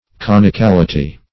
\Con`i*cal"i*ty\